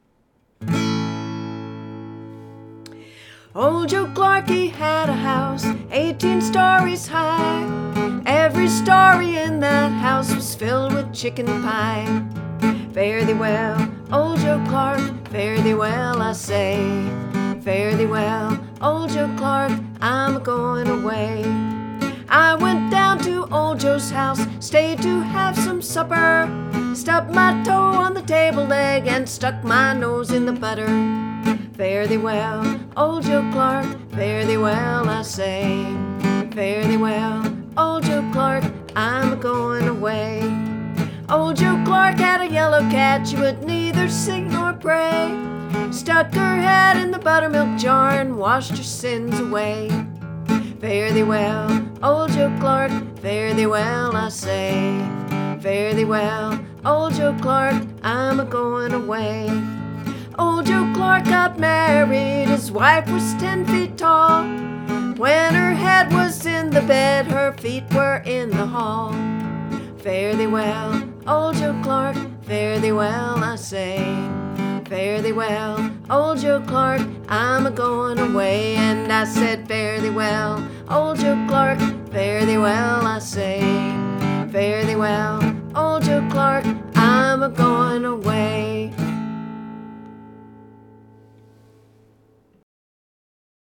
Tune – Old Joe Clark